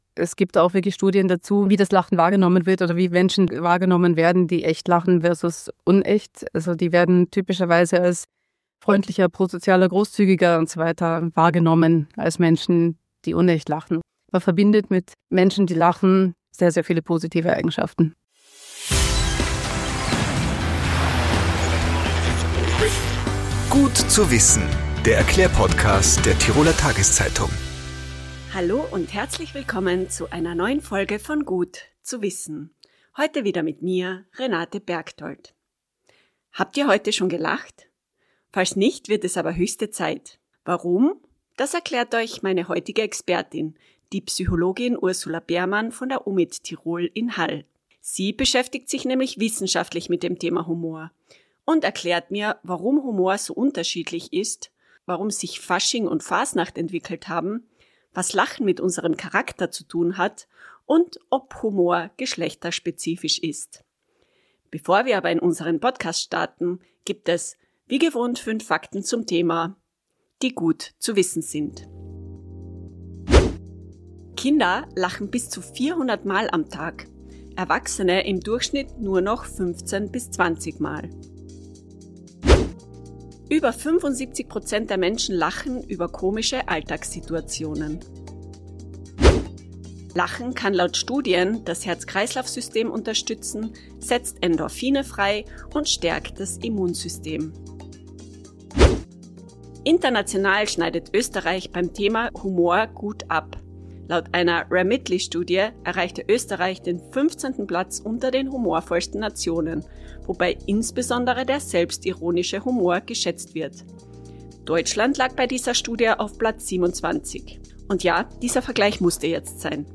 Eine Humorforscherin erklärt die Hintergründe Achtung!
Tipps, Erklärungen und Erfahrungen: Wöchentlich gibt es im "Gut zu wissen"-Podcast der Tiroler Tageszeitung interessante und unterhaltsame Gespräche mit Experten oder Betroffenen rund um die Themen Gesundheit, Lifestyle, Ernährung, Gesellschaft, Freizeit & Beruf, Familie & Beziehungen und sonstige wichtige Alltagsthemen.